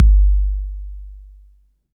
DECAY KICK.wav